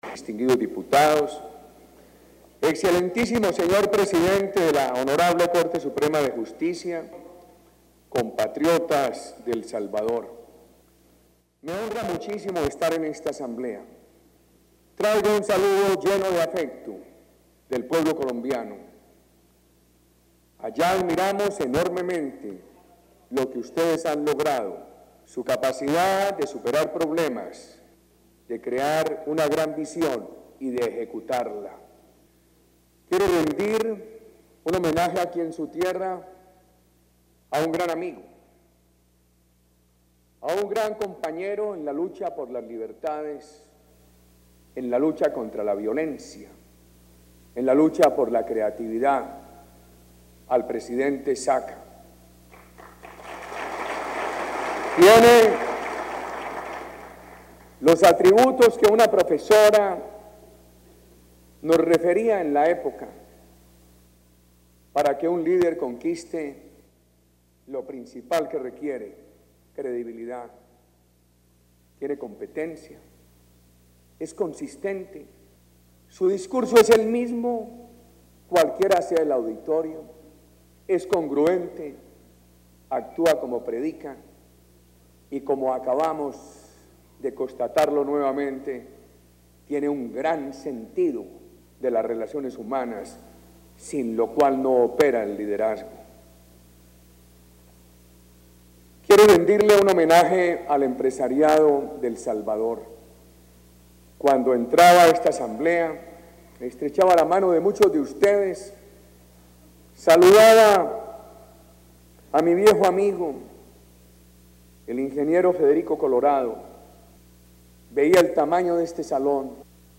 Palabras del Presidente Álvaro Uribe en la conferencia ‘Retos y desafíos de los jóvenes en una sociedad democrática’, celebrada en El Salvador